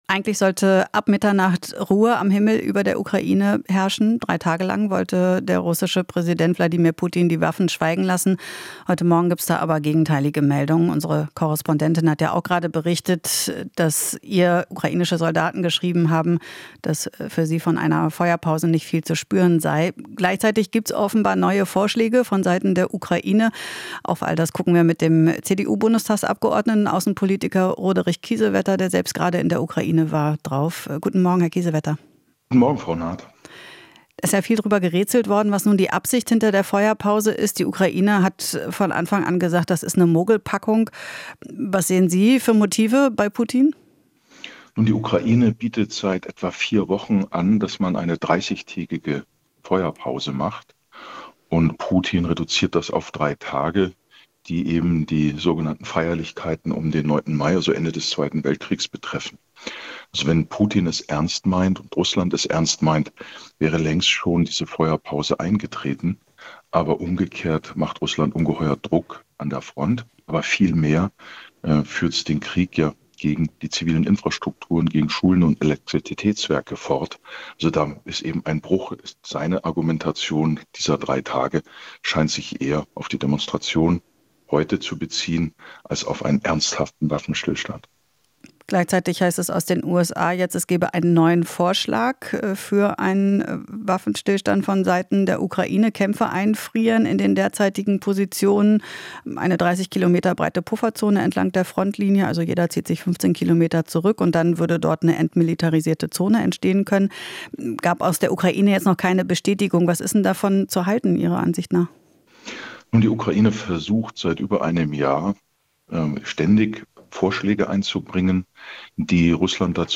Interview - Kiesewetter (CDU): Putin will die Ukraine wehrlos machen